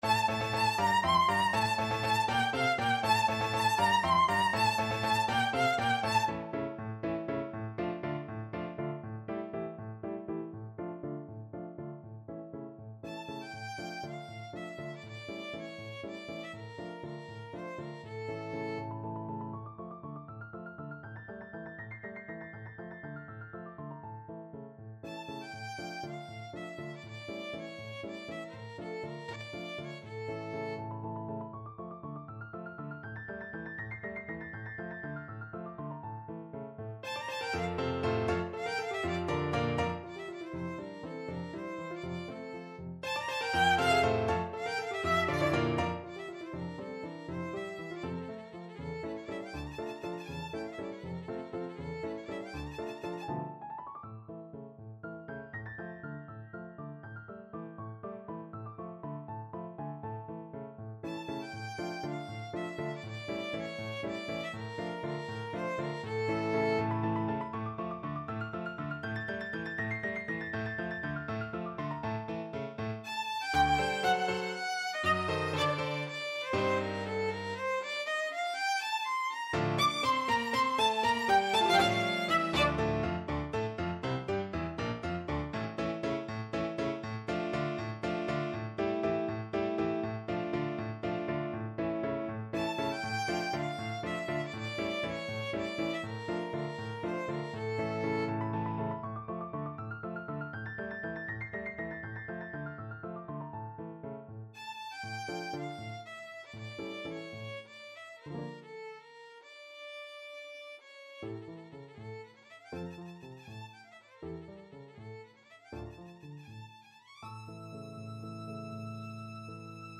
Violin
Allegro vivo (.=80) (View more music marked Allegro)
Ab5-E7
D minor (Sounding Pitch) (View more D minor Music for Violin )
Classical (View more Classical Violin Music)